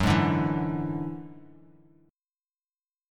F#7sus2#5 chord